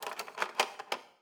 Babushka / audio / sfx / Kitchen / SFX_Cutlery_07.wav
SFX_Cutlery_07.wav